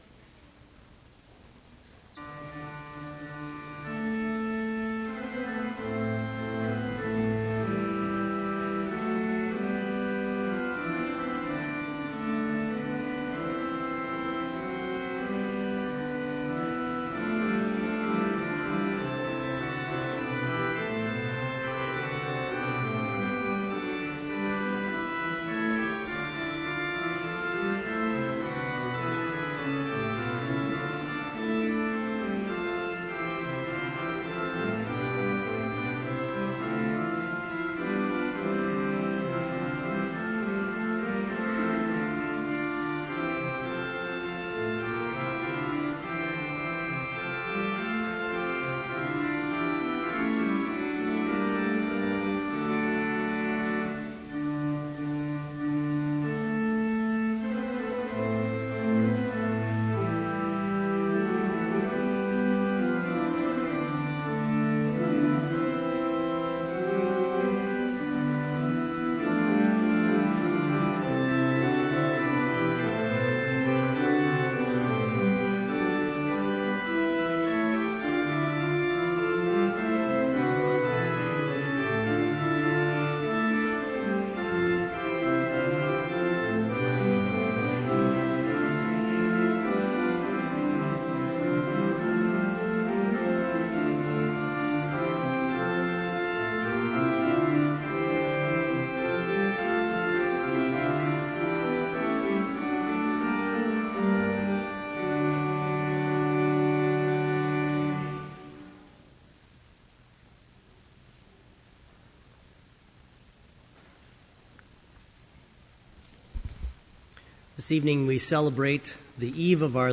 Complete service audio for Evening Vespers - May 8, 2013